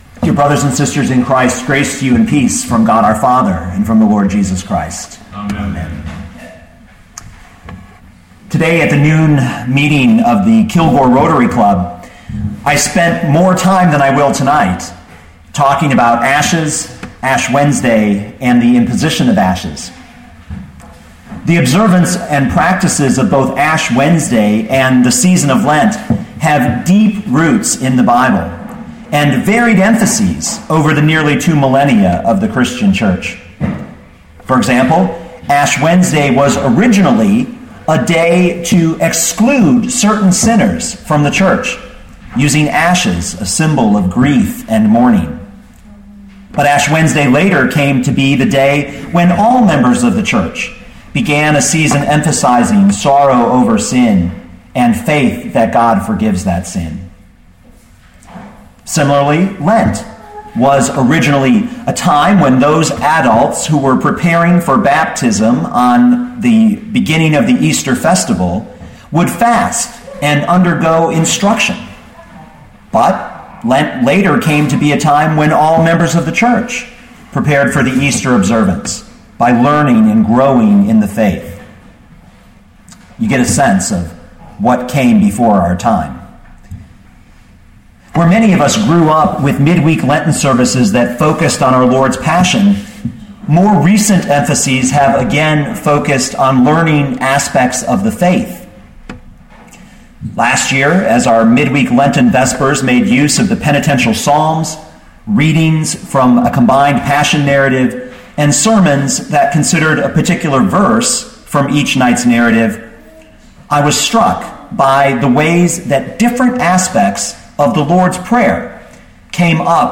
our-father-who-art-in-heaven.mp3